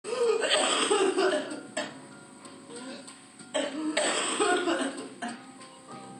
tos seca, irritativa, que puede presentarse en forma de quintas de tos.
Tos_adulto.mp3